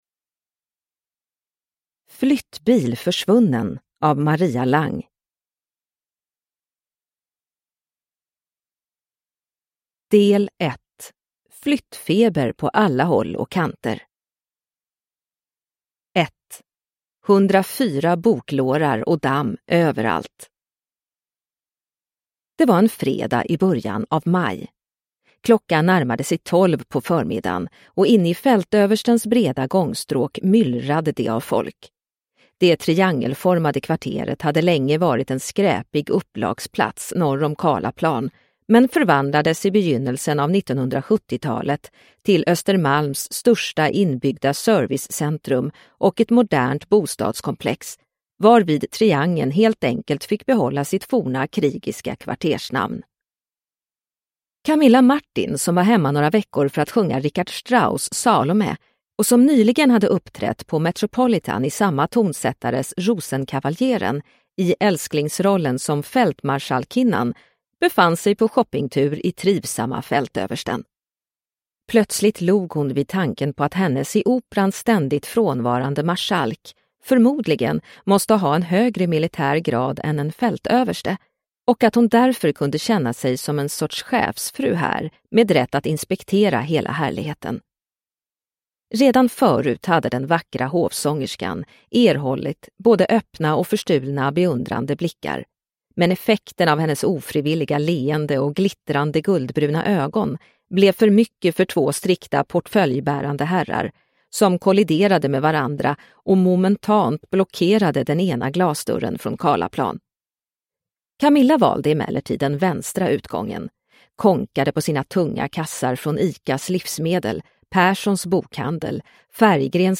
Flyttbil försvunnen – Ljudbok – Laddas ner